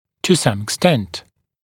[tə sʌm ɪk’stent] [ek-][ту сам ик’стэнт] [эк-]до некоторой степени